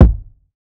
Simple Kick.wav